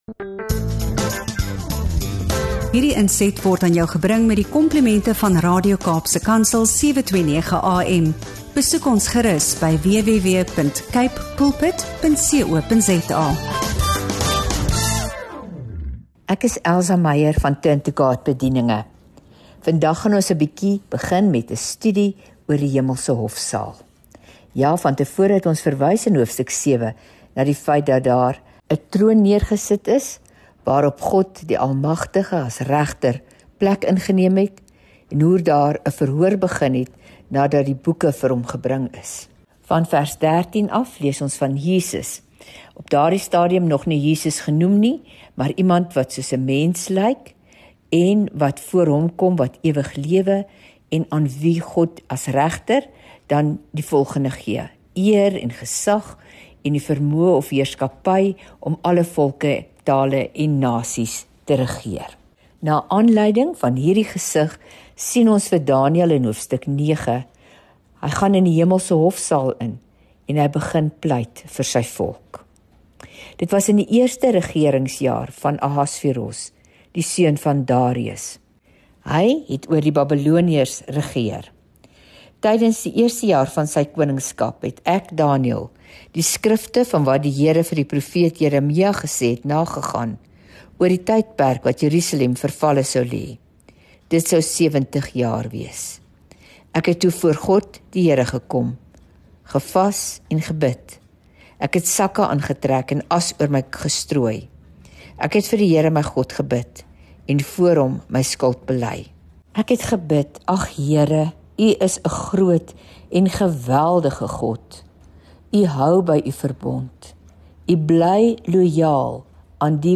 Bybelstudie